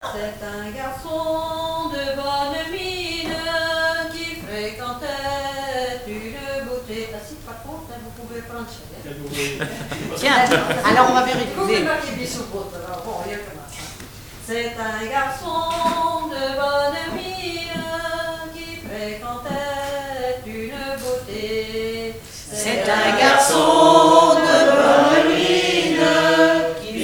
7e festival du chant traditionnel : Collectif-veillée
Pièce musicale inédite